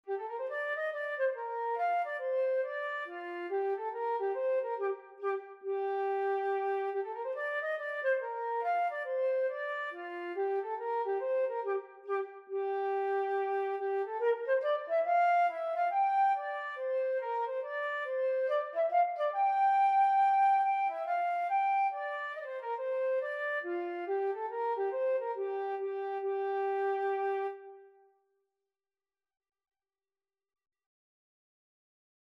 4/4 (View more 4/4 Music)
G minor (Sounding Pitch) (View more G minor Music for Flute )
Flute  (View more Easy Flute Music)
Traditional (View more Traditional Flute Music)